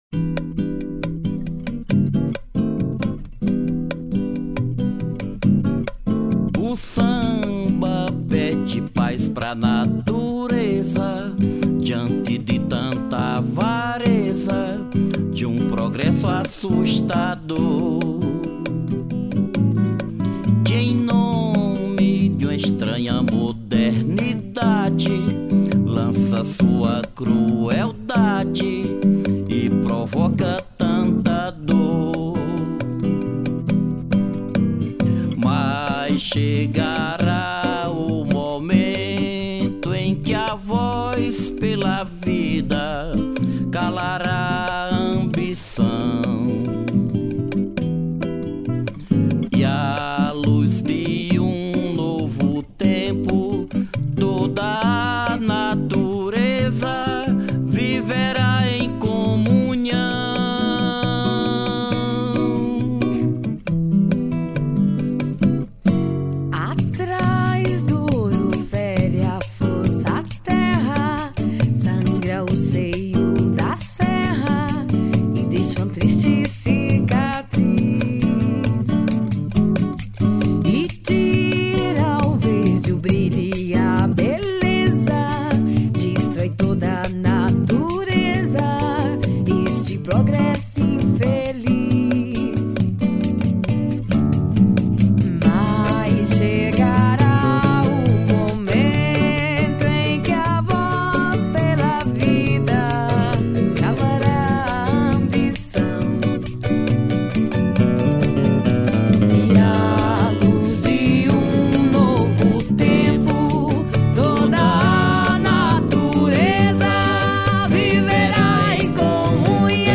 04:30:00   Ciranda